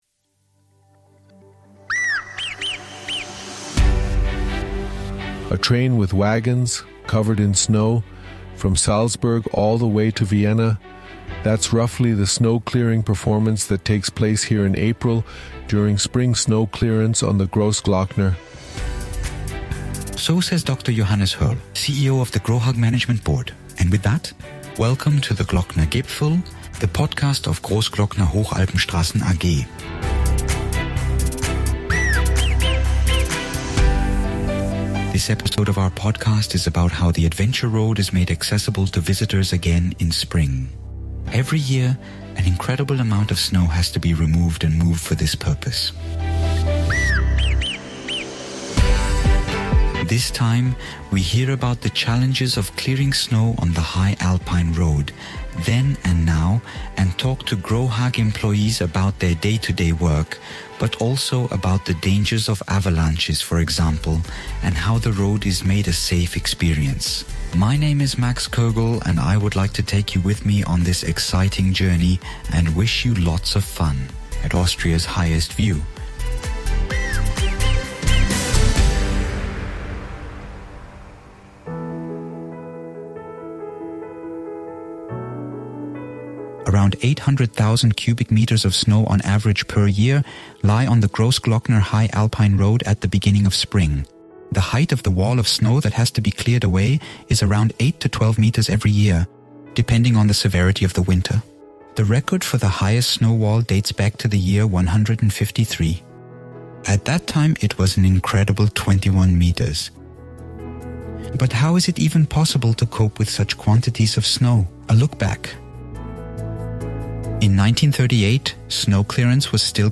Many thanks at this point to the team from "Servus TV" who provided us with some of the original interview recordings with the Grohag employees.